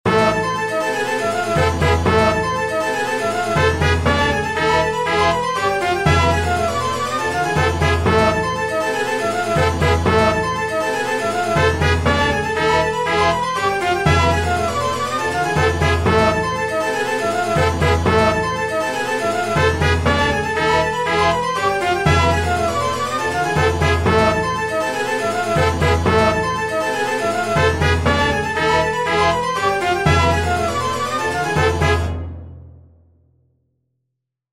Short 120bpm loop in 13edo
13edo_demo_2.mp3